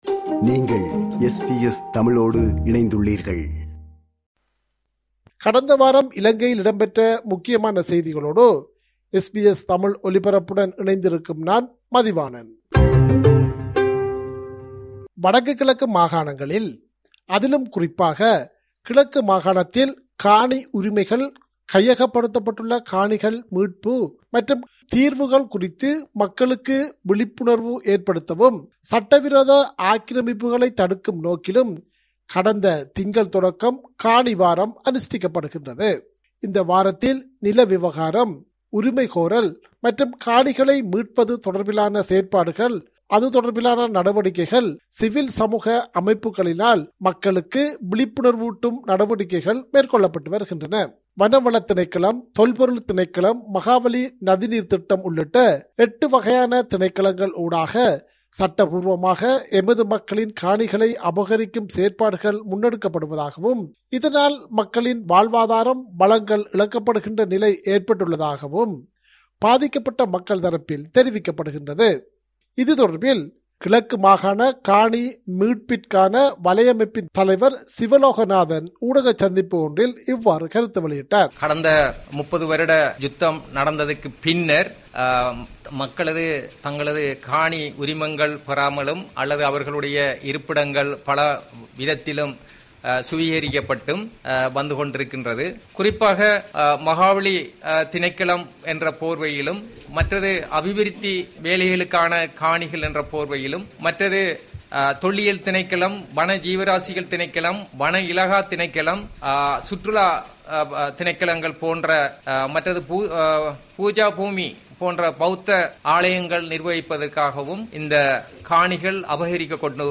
இலங்கை: இந்த வார முக்கிய செய்திகள்